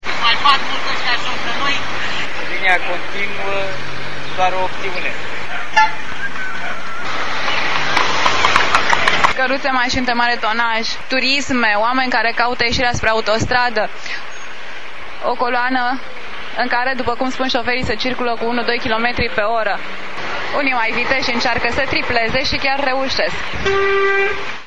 audio trafic pod bora